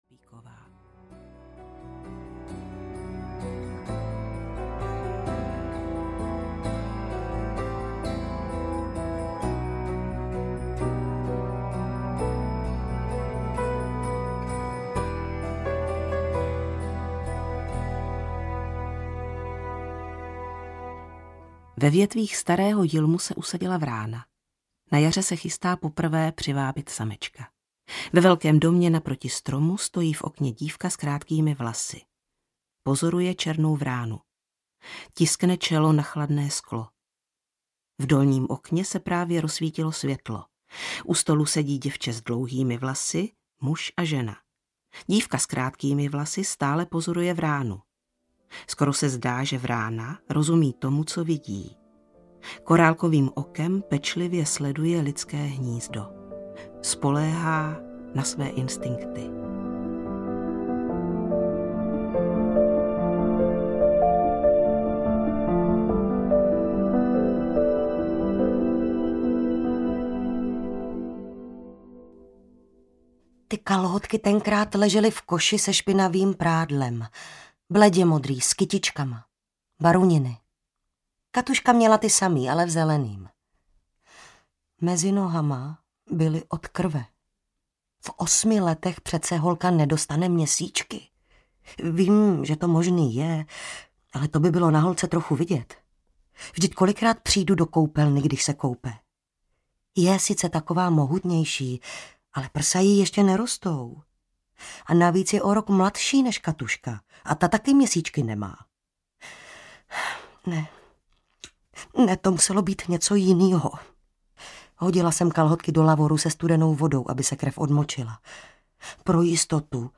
Vrány audiokniha
Ukázka z knihy
• InterpretVeronika Khek Kubařová, Andrea Černá